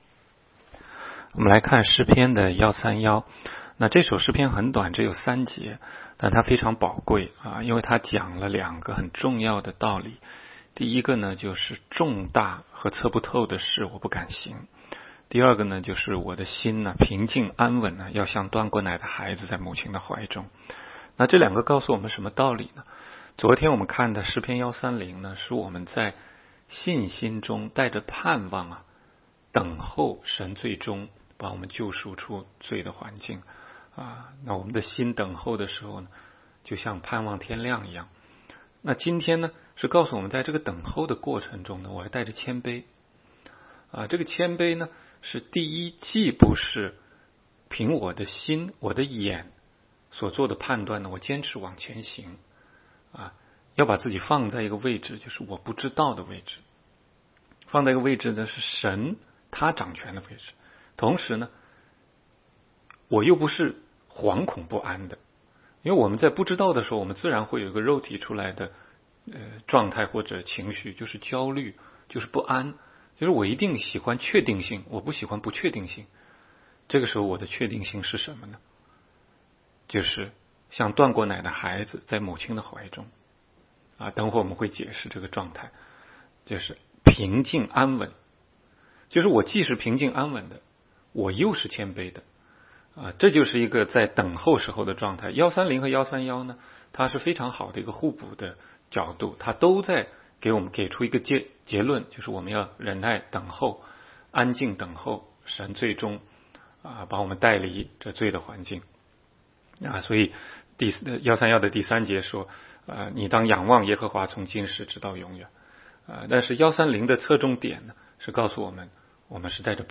每日读经